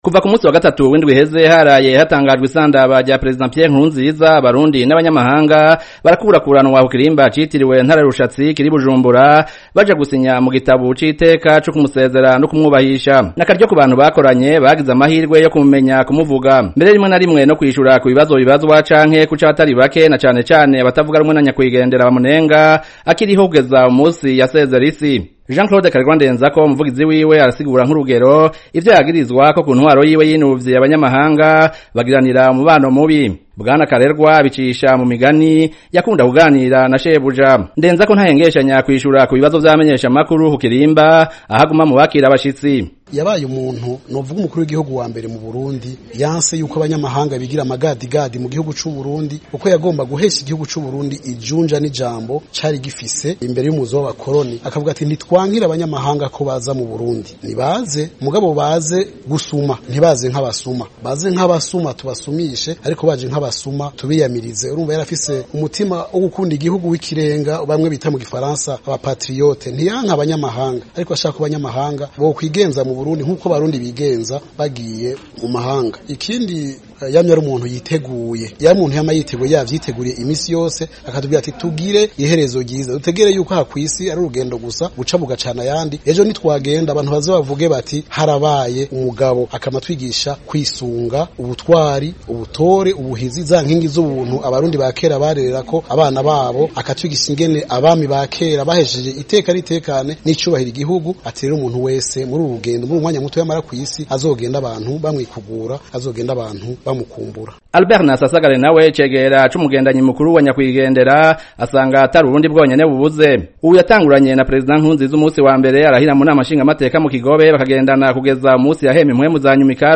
Umviriza bumwe mu buhamya bw'abakoranye na Nkurunziza muri ino nkuru